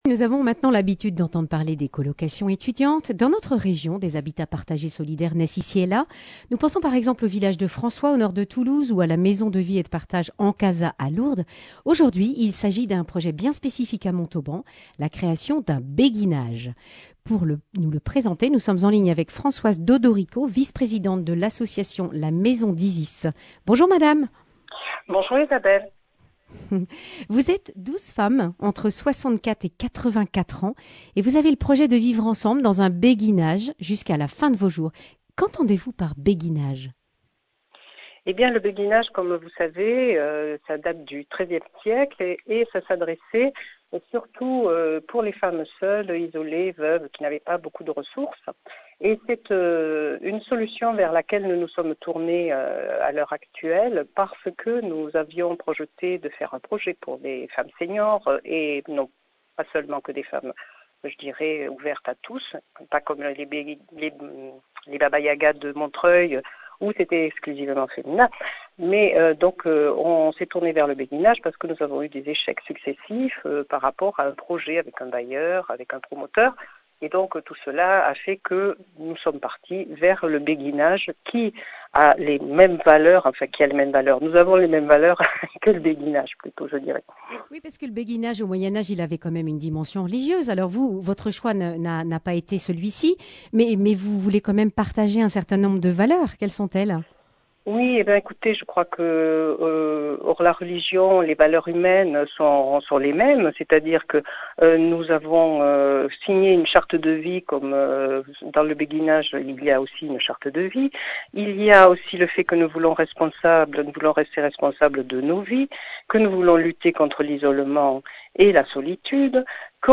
mardi 8 mars 2022 Le grand entretien Durée 11 min